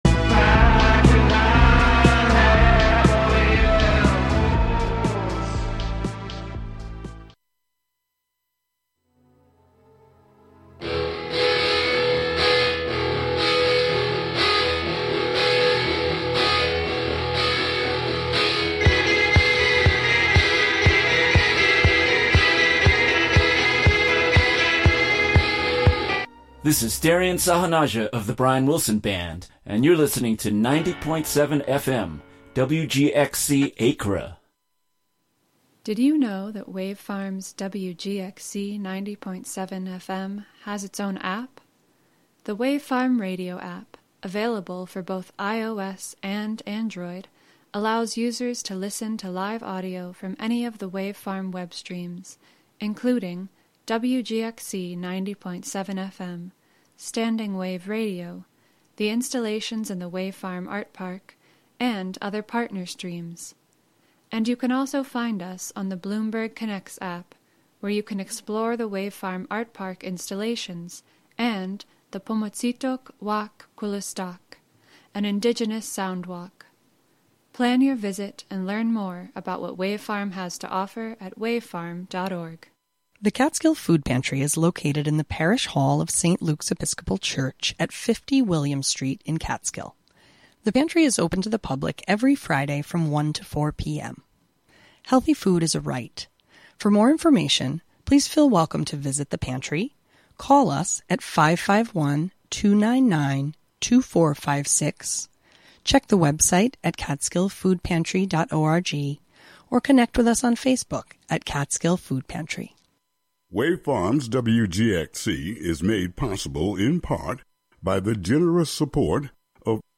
Unsettling, even eerie, songs and instrumental pieces to set the mood for Halloween.